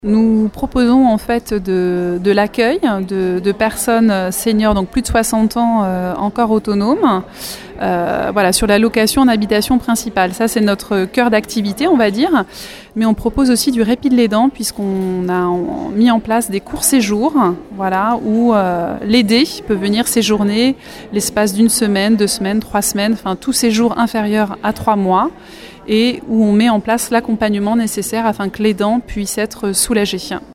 Le 2e Forum des aidants s’est tenu au palais des congrès de Rochefort.